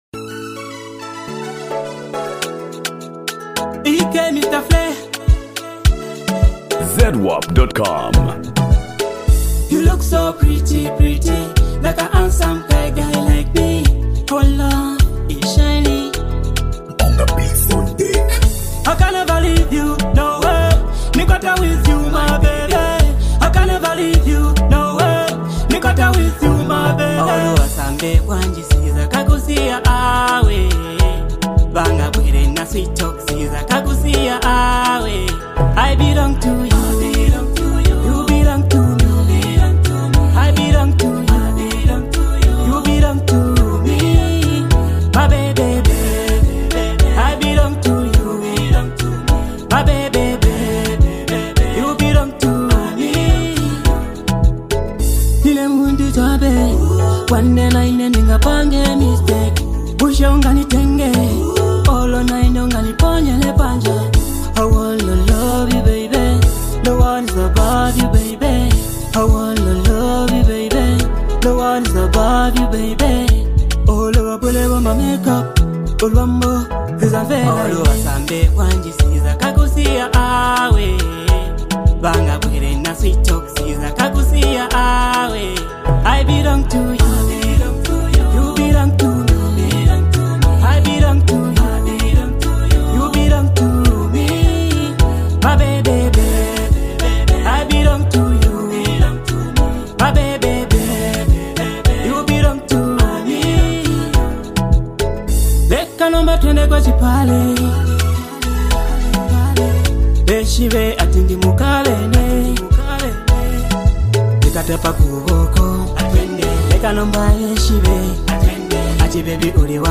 Genre: Afro-Beats
blending catchy melodies with meaningful songwriting.